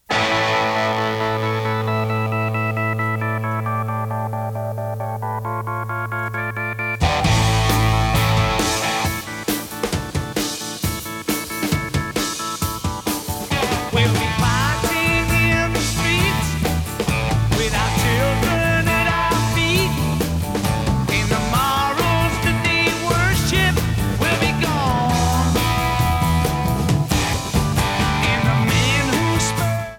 Not the best sound quality.